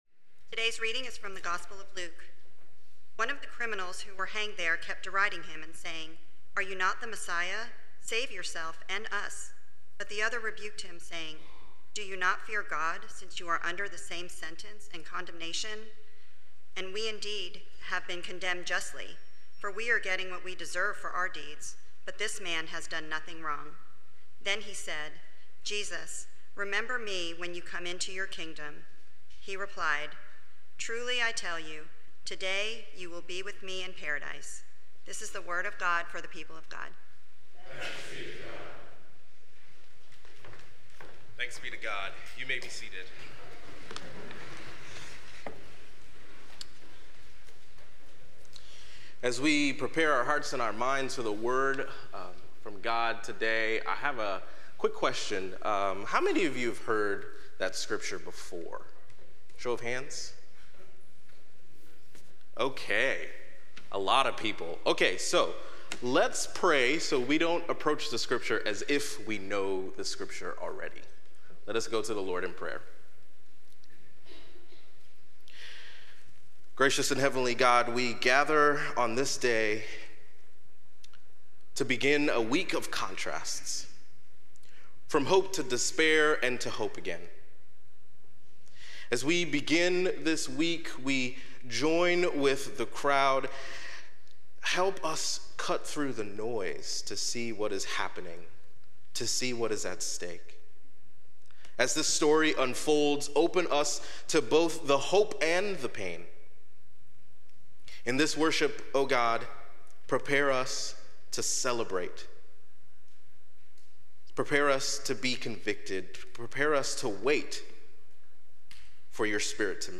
This fourth message of the series draws from both Luke's gospel as we examine not only Jesus' words but also the often-overlooked conversation between the two thieves who hanged on either side of him. We'll learn how these conversations reveal profound truths about the nature of God's grace and learn that true power isn't found in dominance or vengeance, but in Christ's boundless grace. Sermon Reflections: How do you respond to grace when you feel you don't deserve it, like the criminal who recognized Jesus on the cross?